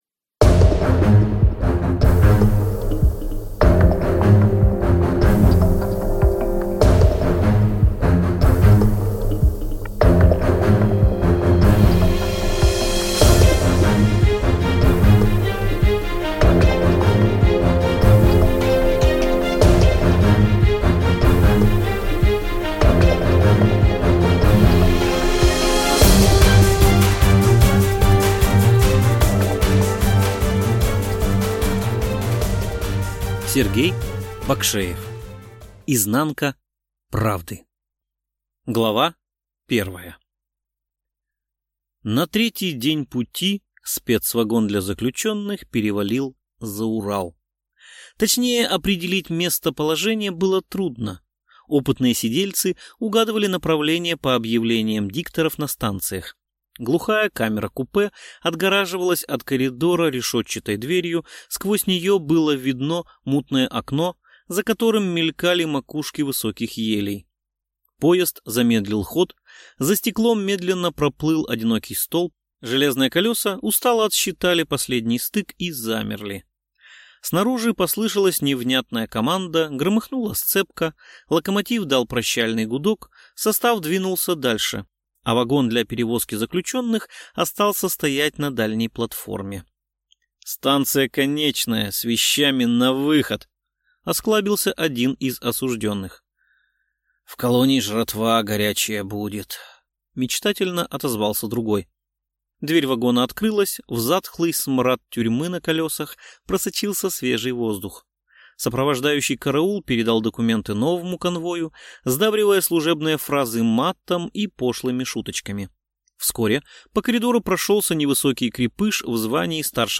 Аудиокнига Изнанка правды | Библиотека аудиокниг